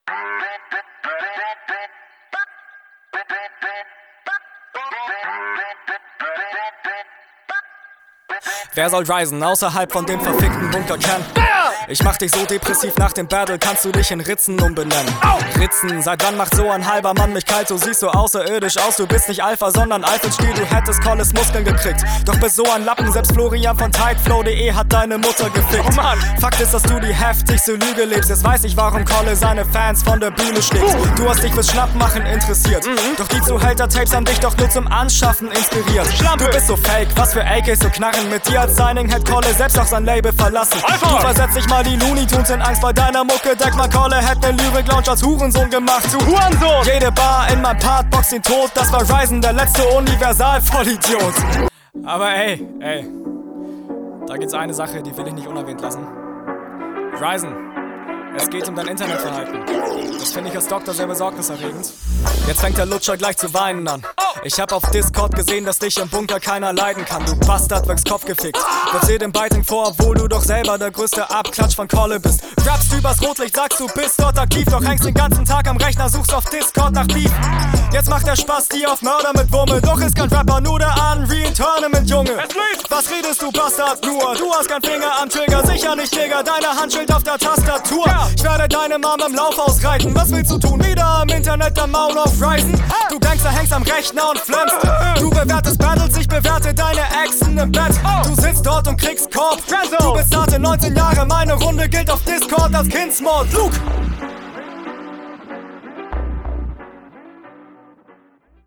Flow: Klingst sofort wesentlich besser, als auf dem 1. Beat.
Der Beat hat schon deutlich mehr Battlerap-Atmo..